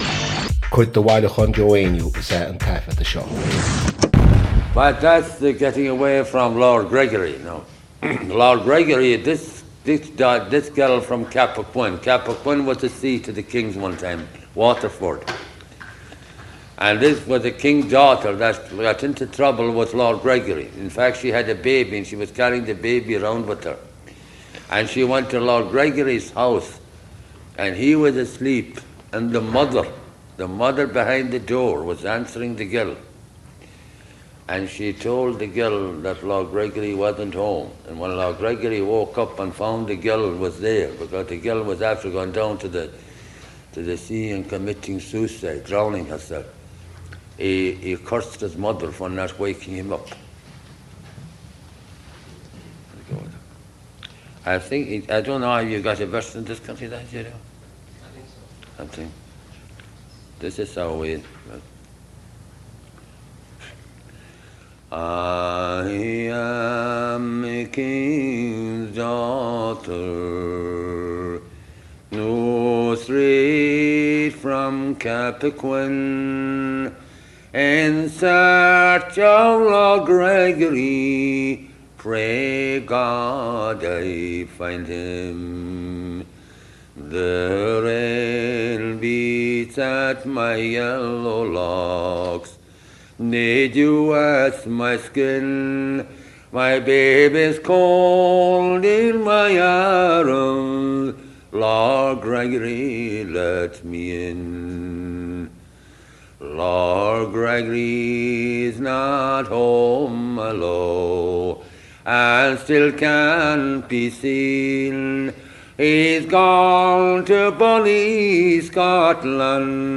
• Catagóir (Category): song.
• Ainm an té a thug (Name of Informant): Joe Heaney.
• Suíomh an taifeadta (Recording Location): University of Washington, United States of America.
• Ocáid an taifeadta (Recording Occasion): day class.
Unlike An Tiarna Randal, however, it was never translated into Irish, but was sung only in English.
This was recorded while Joe was Artist in Residence at University of Washington.